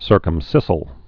(sûrkəm-sĭsĭl, -ĭl)